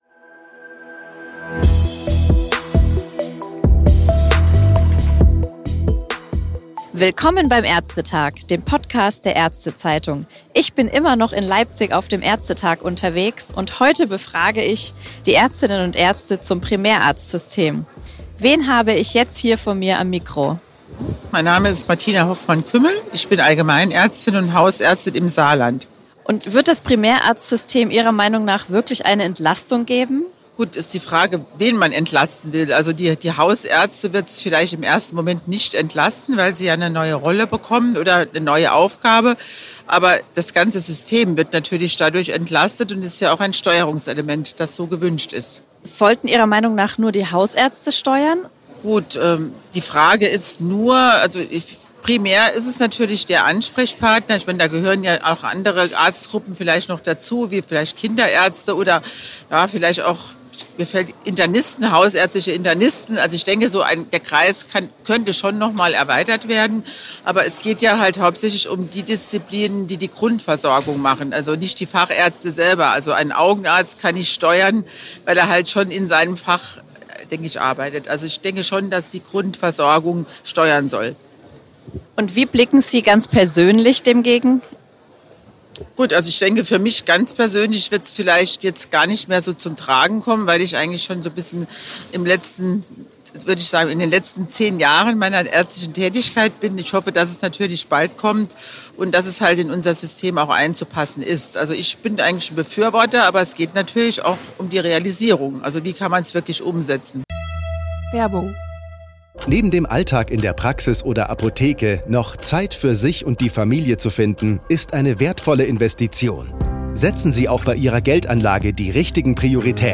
In einer „ÄrzteTag vor Ort“-Podcastumfrage in Leipzig äußern sich vier Ärztinnen und Ärzte aus unterschiedlichen Fachrichtungen.